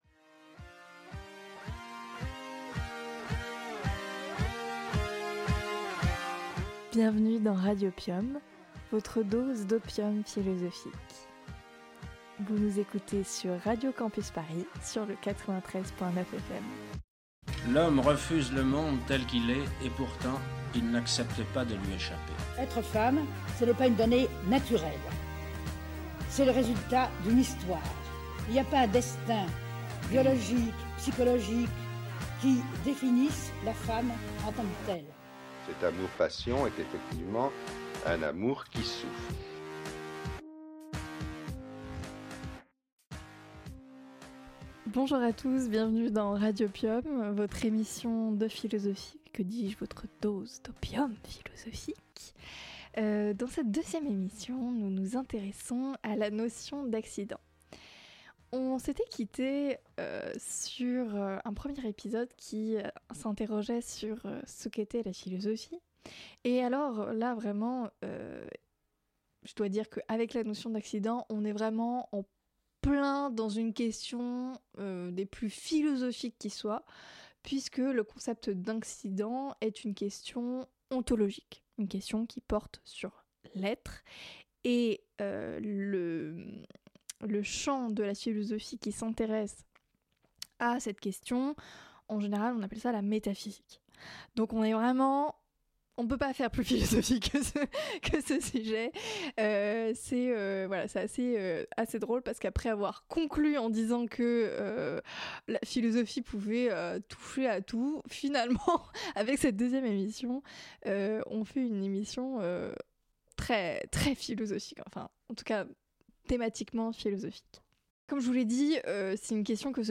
Type Magazine Culture